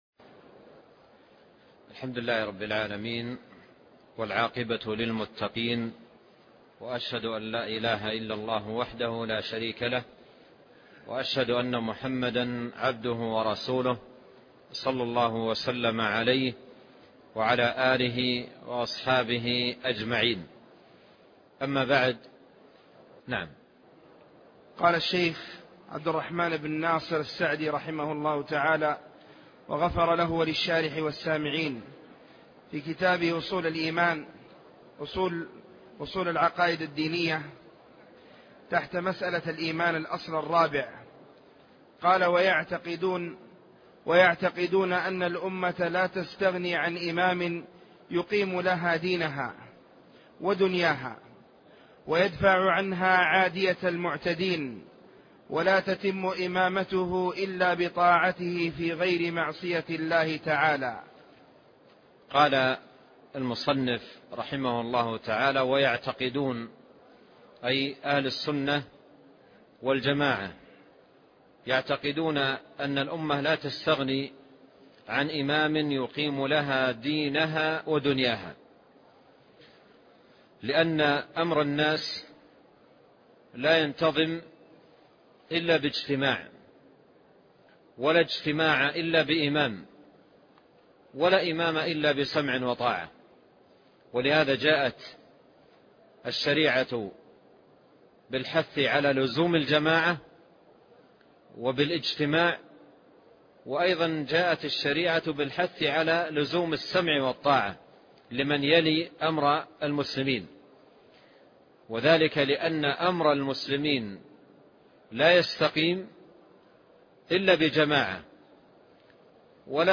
شرح رسالة أصول العقائد الدينية 14 - ويعتقدون أن الأمة لا تستغني عن إمام